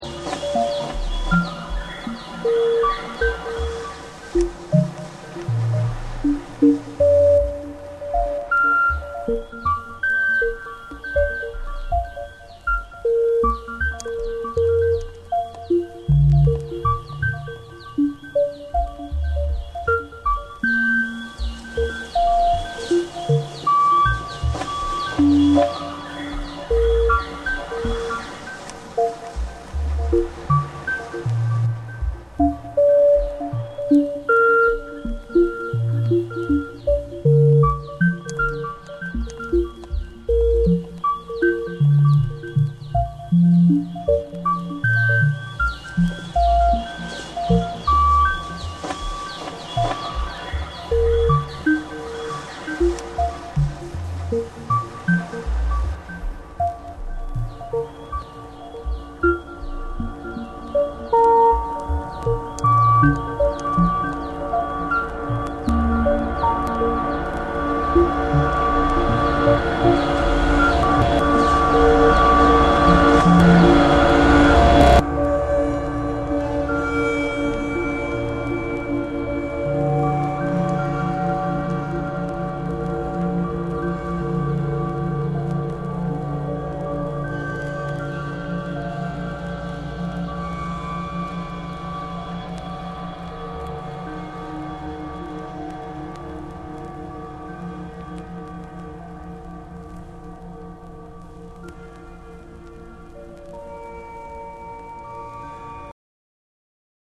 JAPANESE / NEW AGE